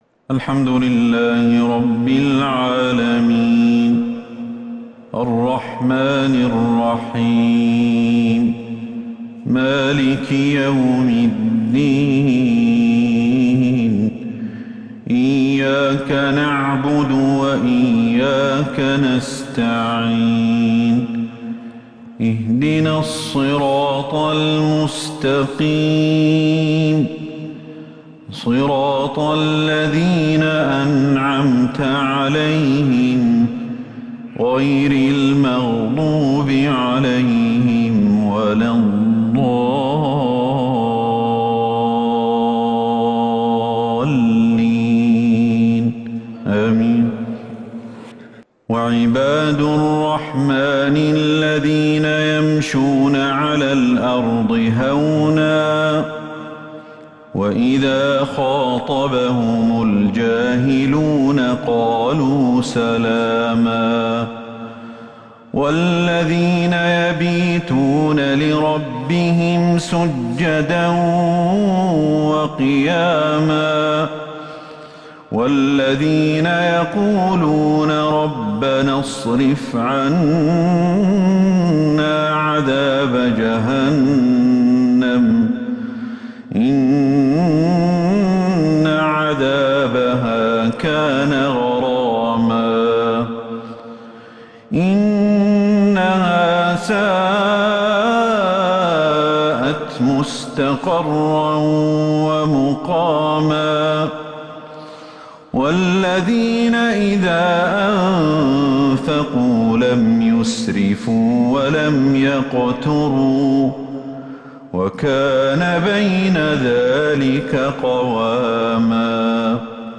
عشاء الإثنين 5 ربيع الأول 1443هـ آواخر سورة {الفرقان} > 1443 هـ > الفروض - تلاوات الشيخ أحمد الحذيفي